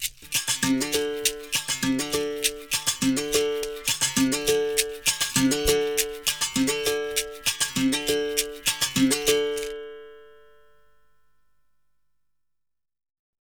Berimbao_Samba_100_2.wav